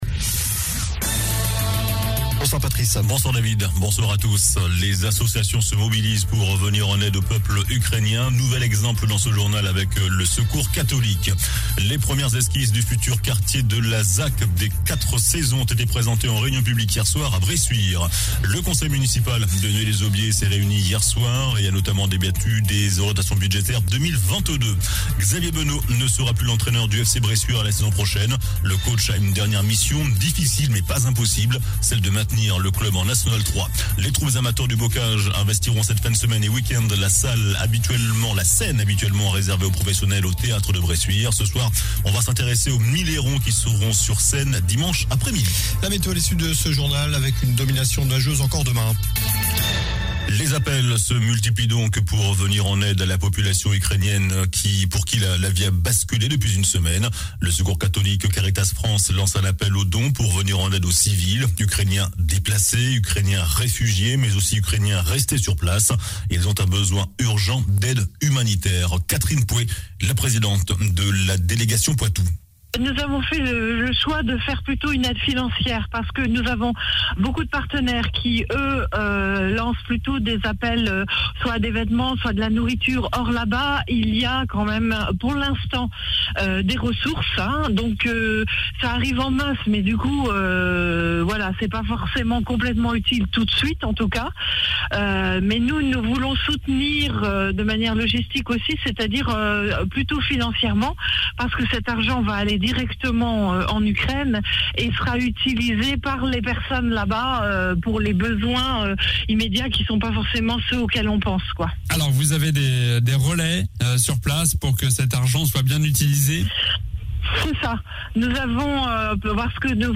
JOURNAL DU JEUDI 03 MARS ( SOIR )